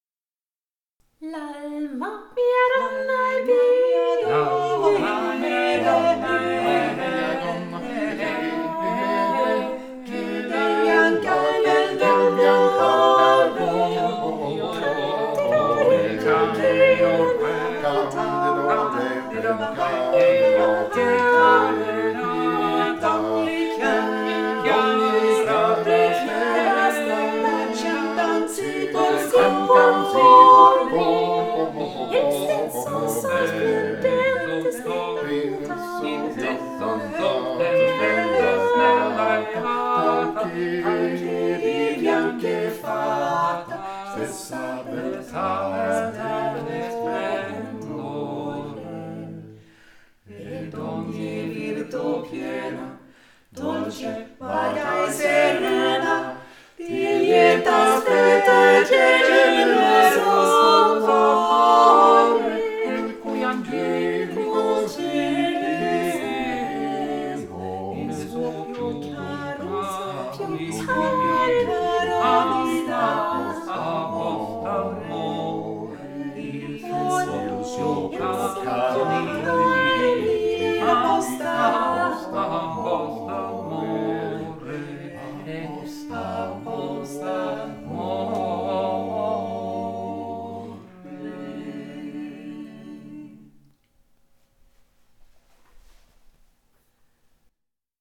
Vokalensemblen KALK